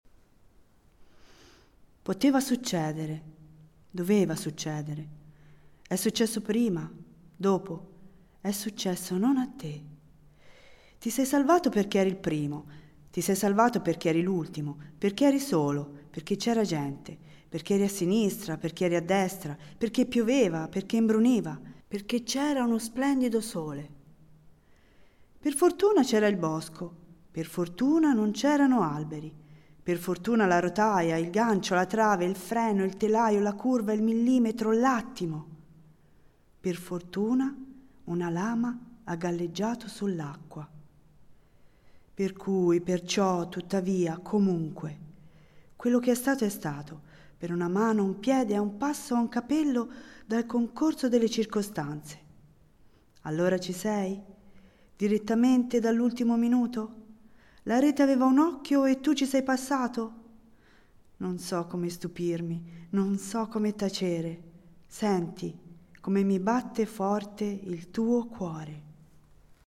dallo spettacolo del 10 Luglio 2015
Nell’ascolto della recitazione degli attori, diventa esplicita anche un’altra caratteristica comune a molte delle sue poesie, cioè la loro teatralità intrinseca, che forse deriva proprio dall’immediatezza del loro stile: questo permette di interpretarle dando voce e vita direttamente agli stessi protagonisti delle poesie.